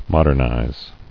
[mod·ern·ize]